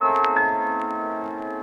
Keys_03.wav